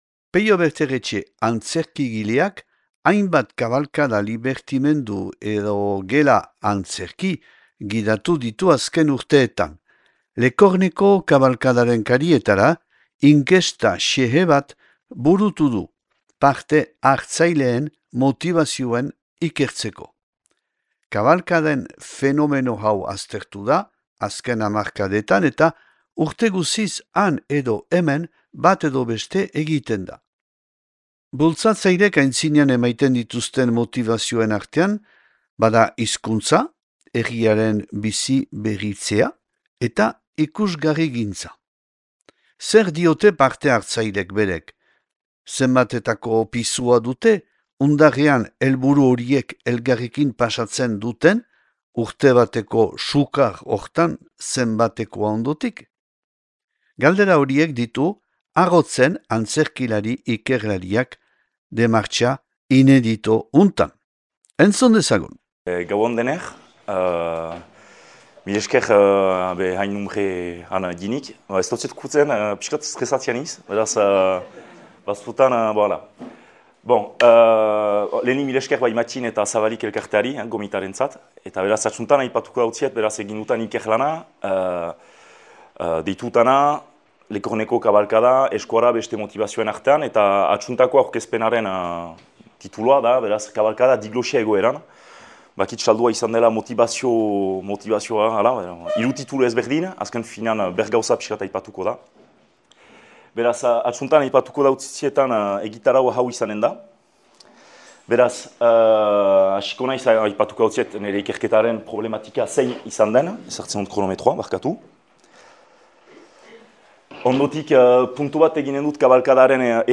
(2025. otsailaren 20an grabatua Donapaleun « Otsail Ostegunak » hitzaldiaren zikloan Zabalik elkartearekin.